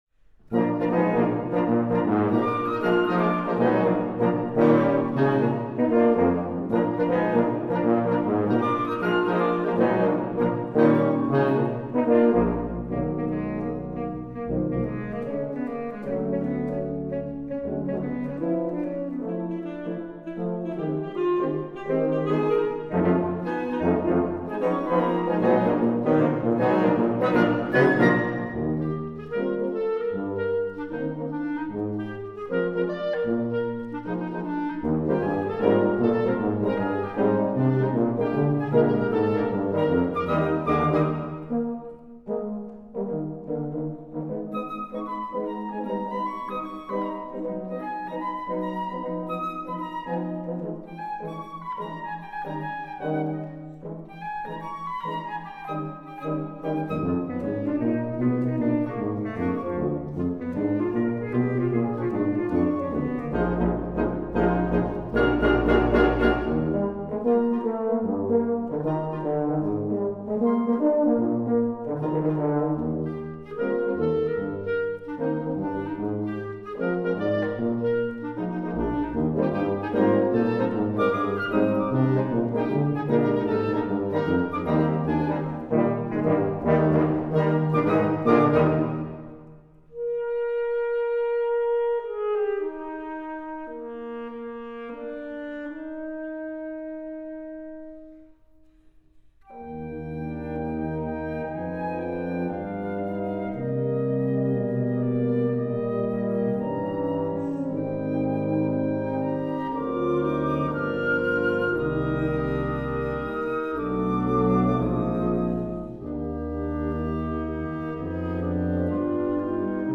They requested an Okinawan folk song in jazz style
Bladmuziek voor flexibel ensemble.